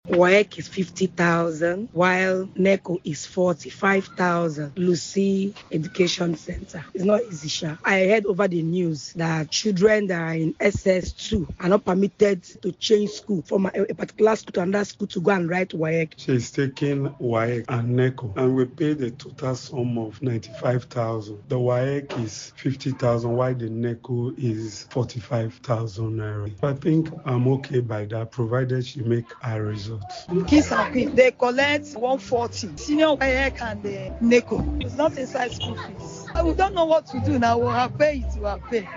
Listen to a cross section of parents sharing their concerns below: